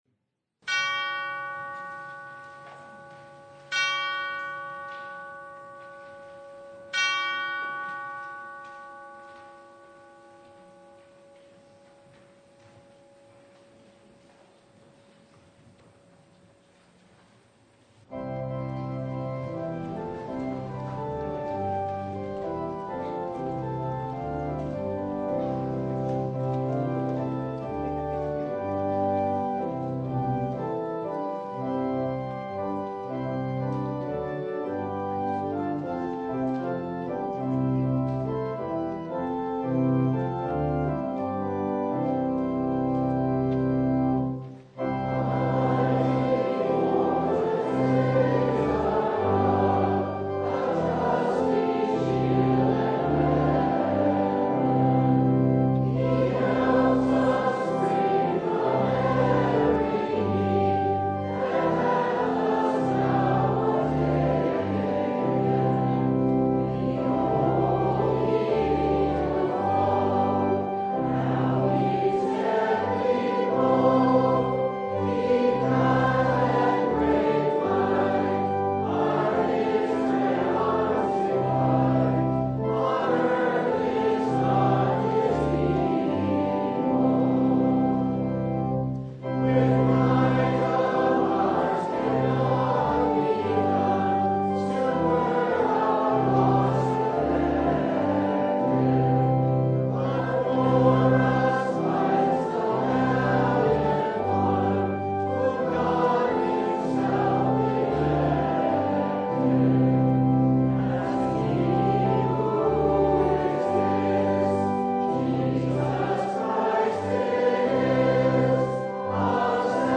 Matthew 11:12-19 Service Type: The Festival of the Reformation What was the Reformation about?